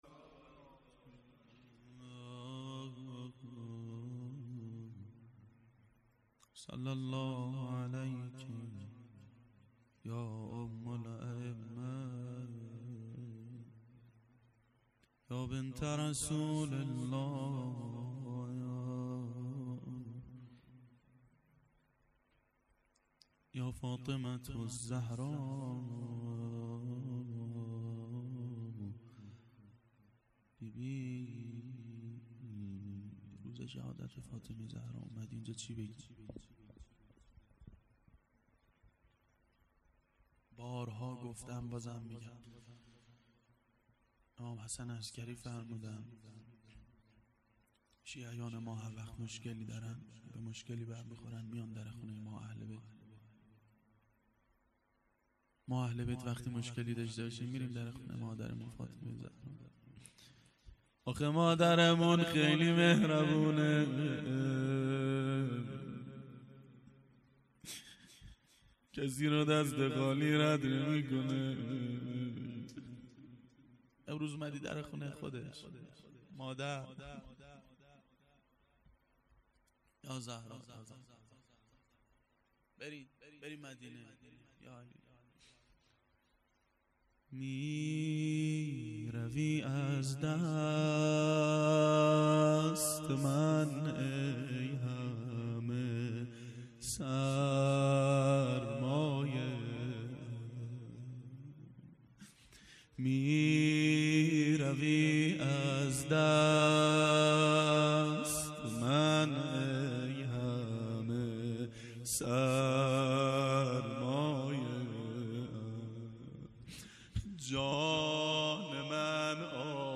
روضه حضرت زهرا و امام حسین علیهماالسلام
ظهر شهادت حضرت زهرا سلام الله علیها1392 هیئت شیفتگان حضرت رقیه س